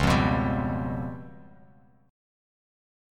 C#7b5 chord